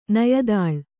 ♪ nayadāḷ